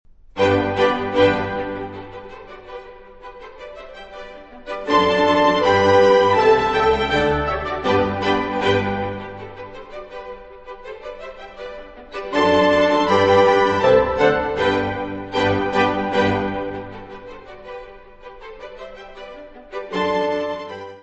Music Category/Genre:  Classical Music
Presto.